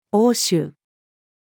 応酬-female.mp3